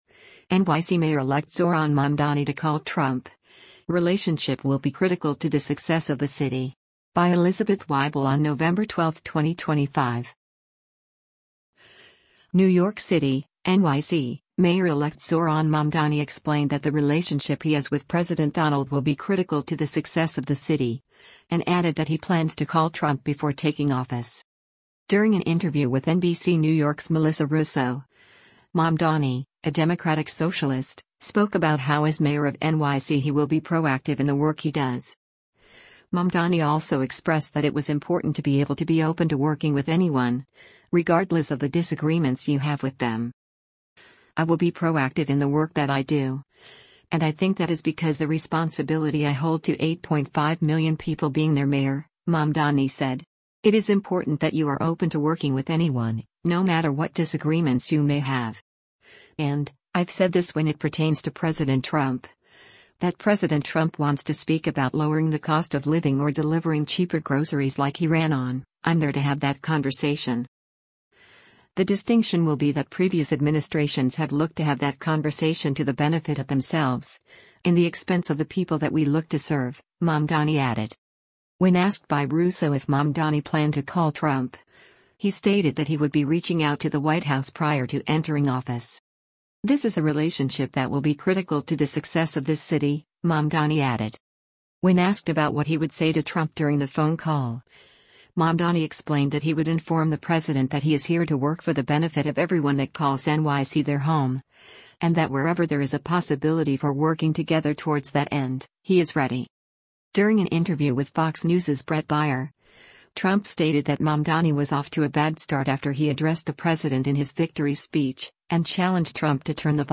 NEW YORK, NEW YORK - NOVEMBER 11: New York Mayor-Elect Zohran Mamdani gives brief remarks